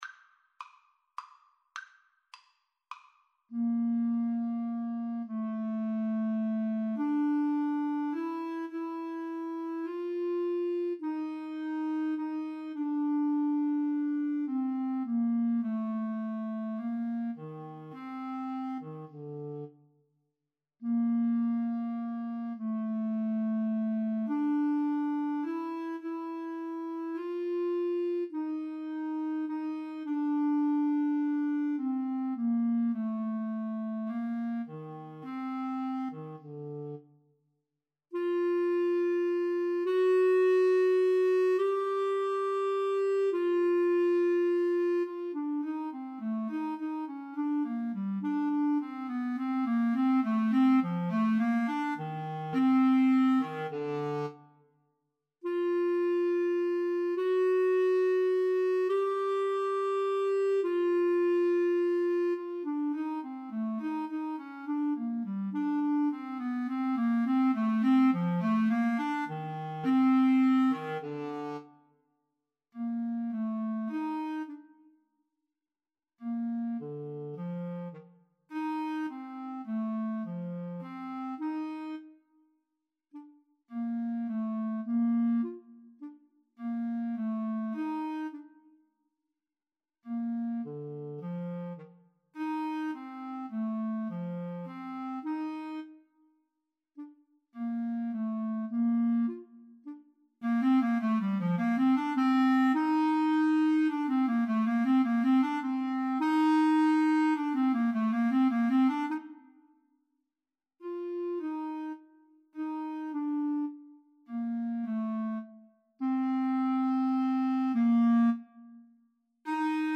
3/4 (View more 3/4 Music)
Con Grazia = c. 104